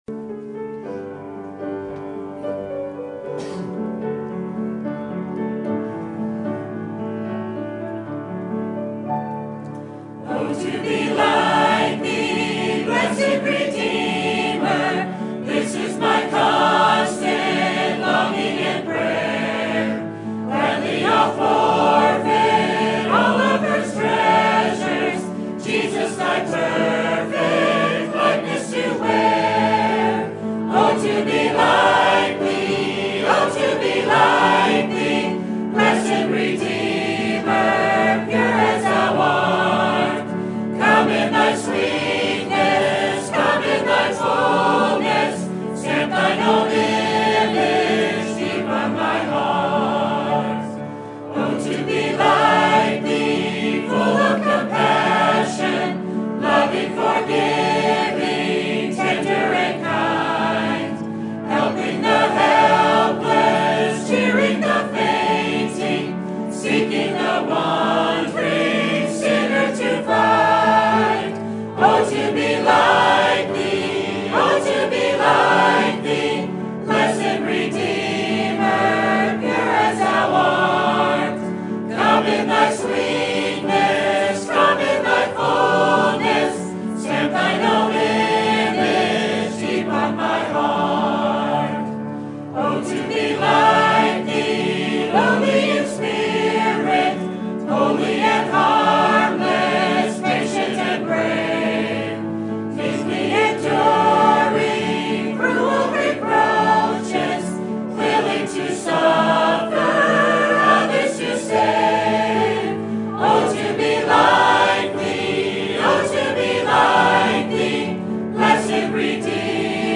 Sermon Topic: General Sermon Type: Service Sermon Audio: Sermon download: Download (24.88 MB) Sermon Tags: Acts Jesus Weeping Word